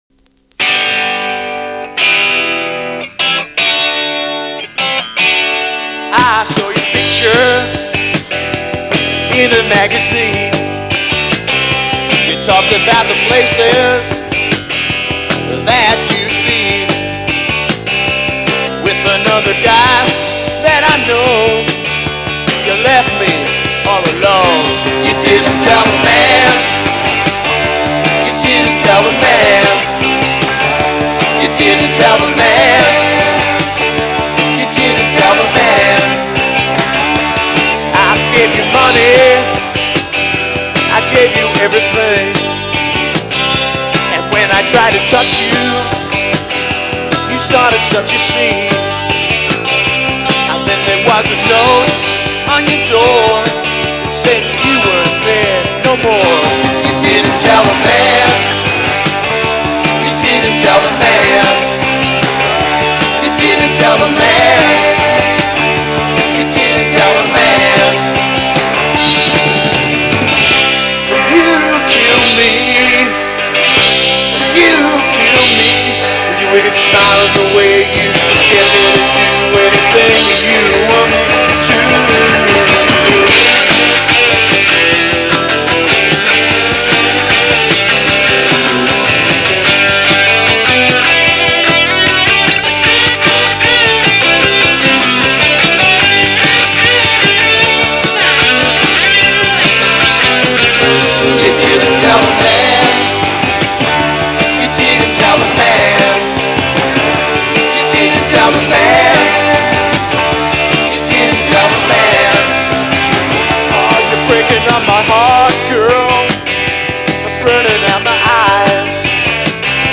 aussie power popの隠れた